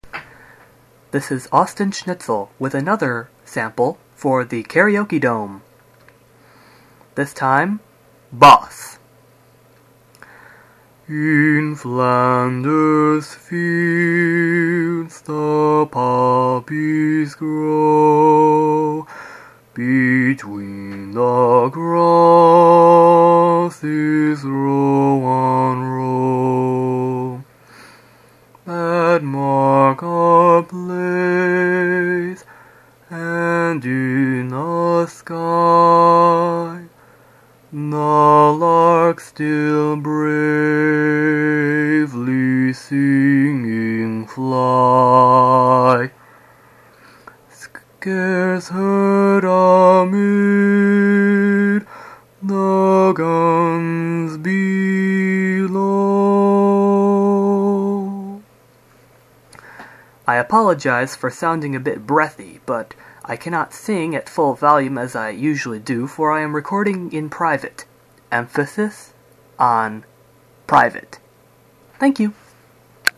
Bass Sample: "In Flanders Fields"
BassSample.MP3